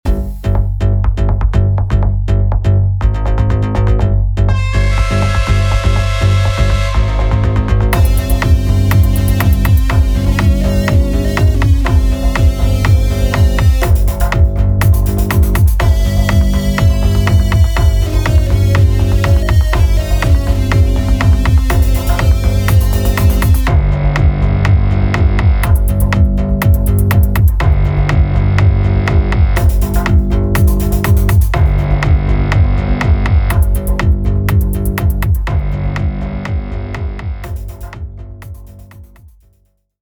Género: Electrónica / Hard techno.